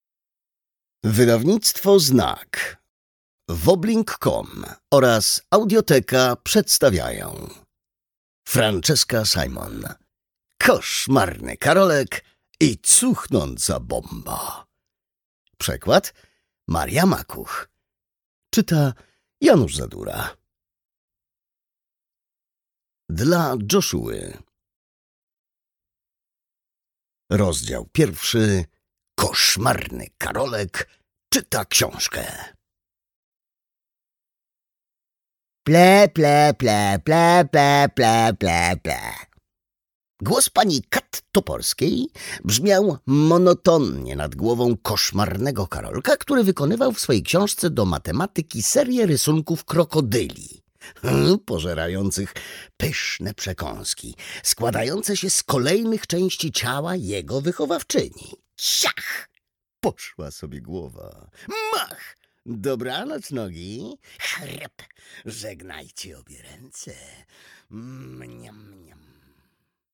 Koszmarny Karolek i cuchnąca bomba - Simon Francesca - audiobook + książka - Legimi online